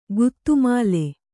♪ guttu māle